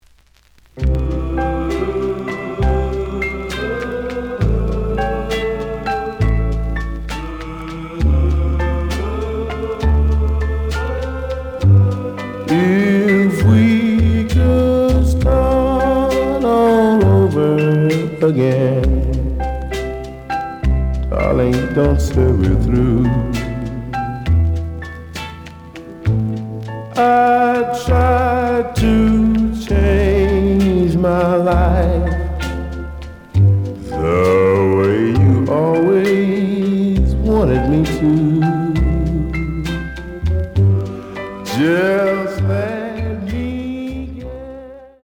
The audio sample is recorded from the actual item.
●Genre: Rhythm And Blues / Rock 'n' Roll
Looks good, but slight noise on A side.